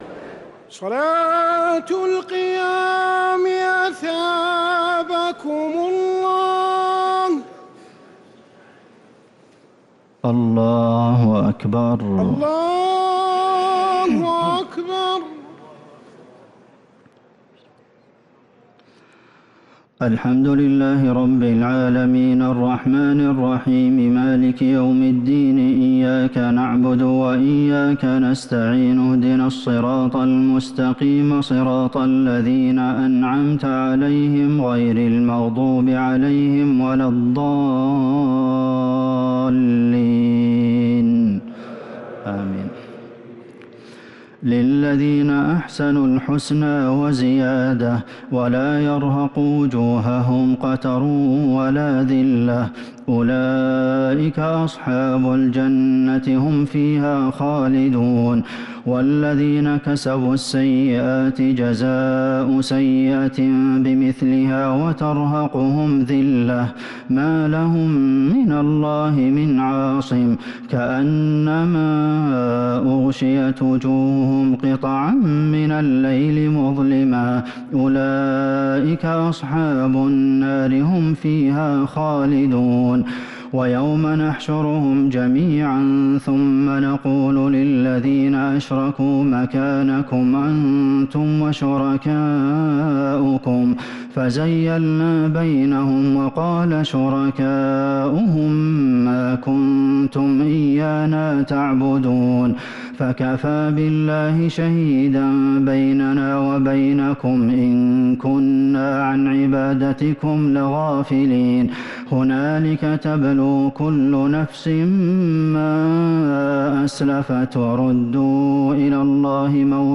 تراويح ليلة 15 رمضان 1447هـ خواتيم سورة يونس {26-109} | Taraweeh 15th Ramadan niqht 1447H Surat Yunus > تراويح الحرم النبوي عام 1447 🕌 > التراويح - تلاوات الحرمين